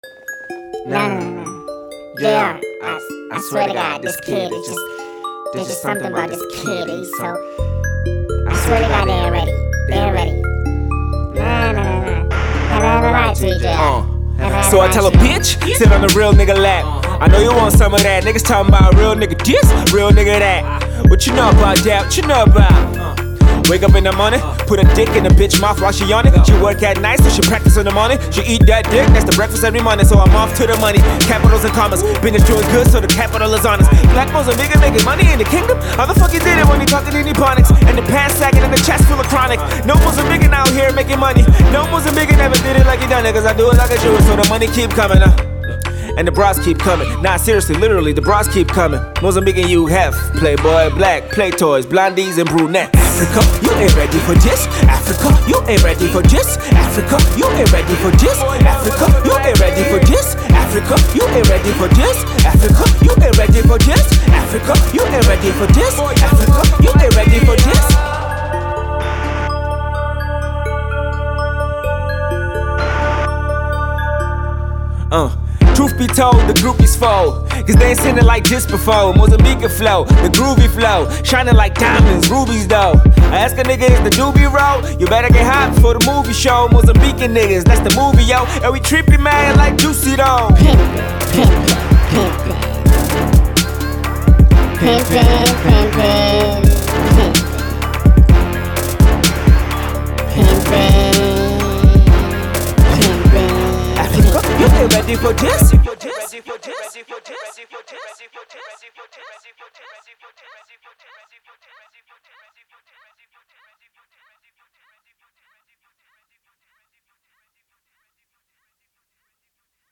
Genero: Hip-Hop/Rap